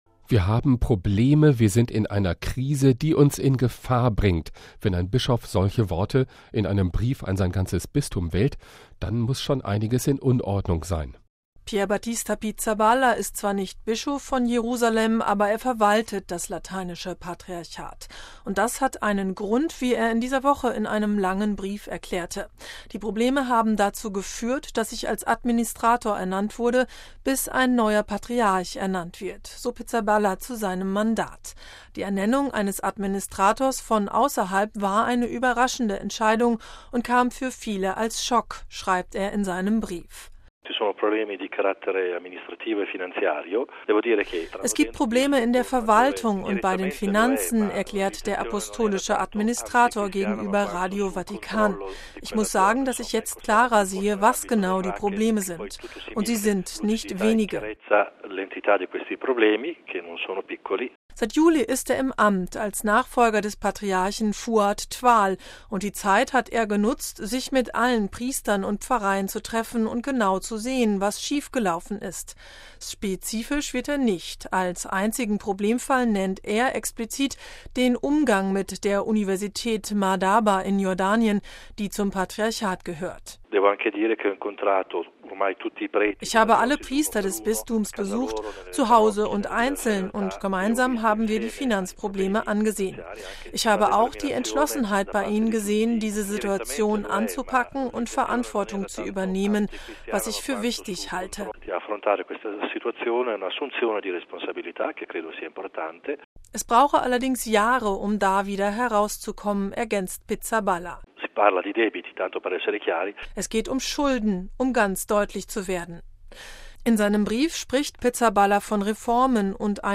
„Es gibt Probleme in der Verwaltung und bei den Finanzen“, erklärt der Apostolische Administrator gegenüber Radio Vatikan.